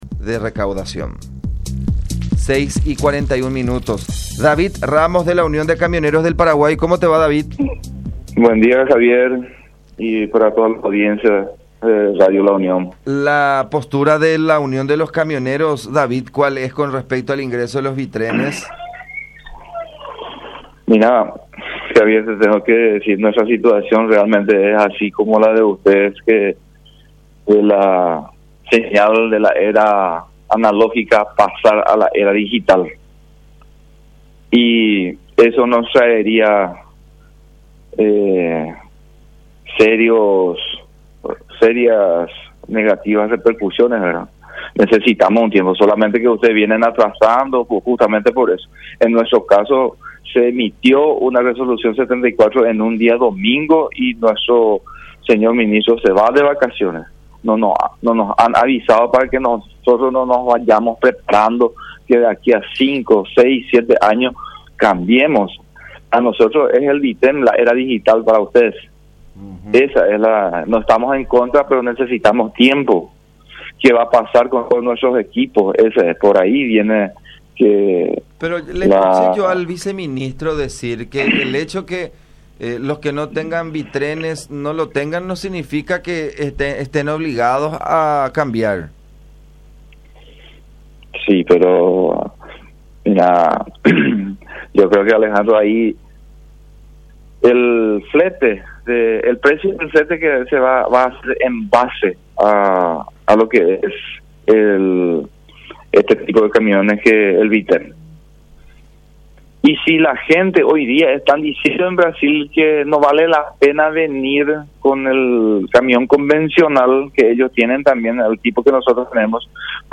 “Lo que estamos haciendo es una acción de proteccionismo”, dijo en contacto con La Unión R800 AM.